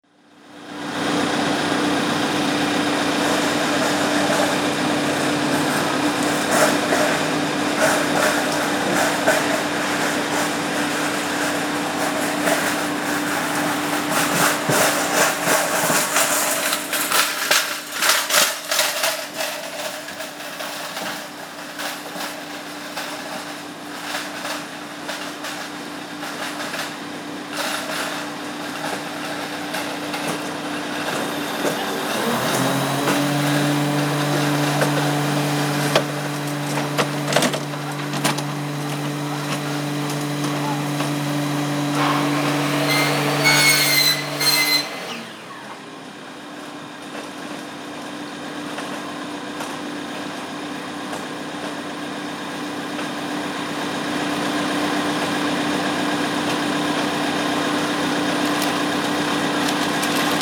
A door creaking in the wind
Schoeps CCM M/S and Tascam Dr60MkII Binnenshuis